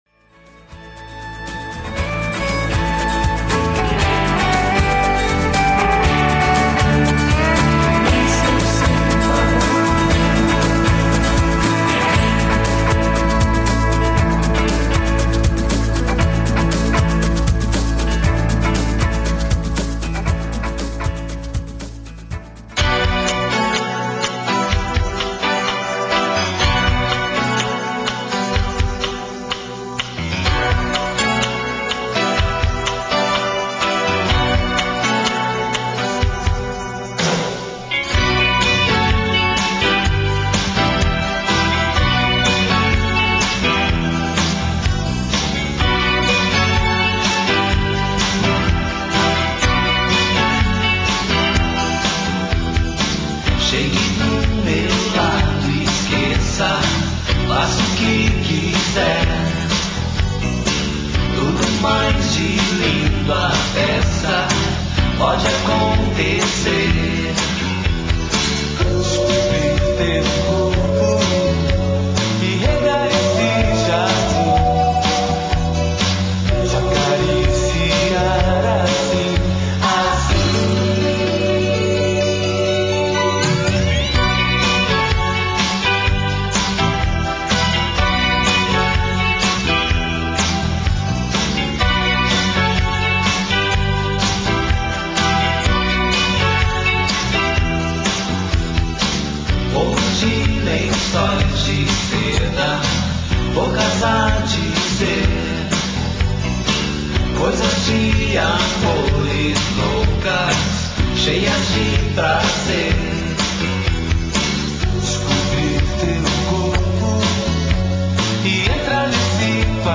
com algum tempero de música Country